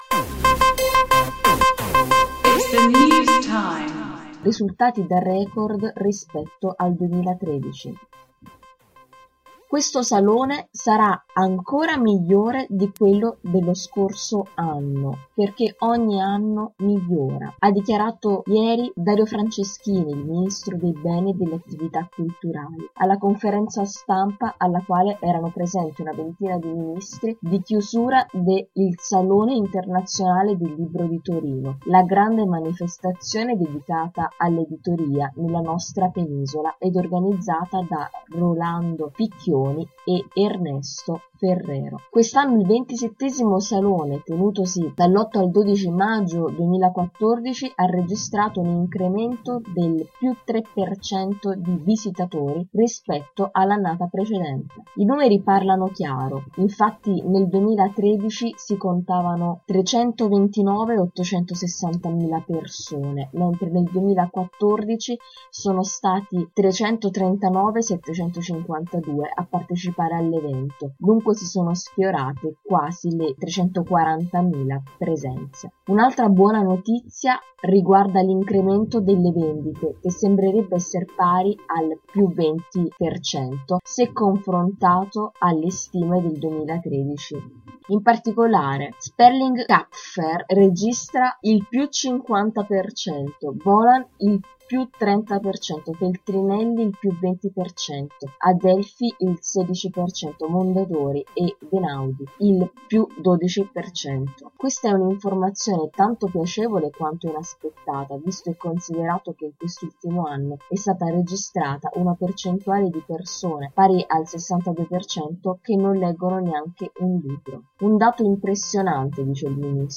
Se vuoi ascoltare l’articolo letto dalle nostre redattrici